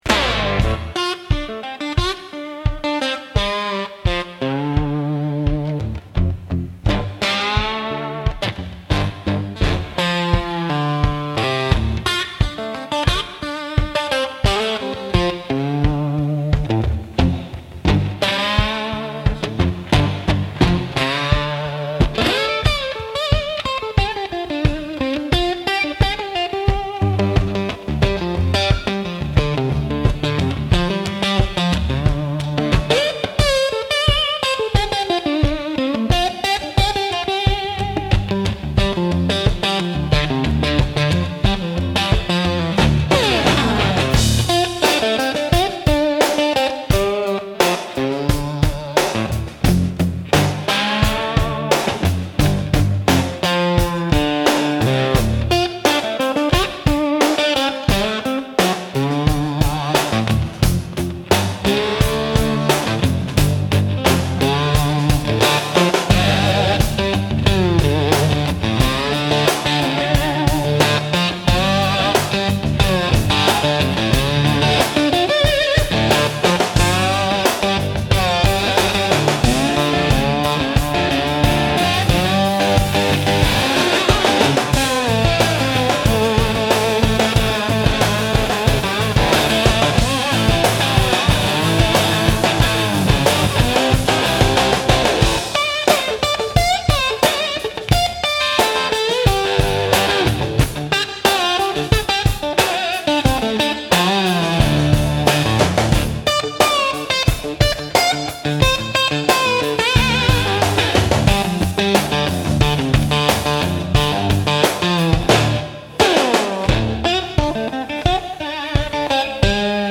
Instrumental - Crossroads on Fire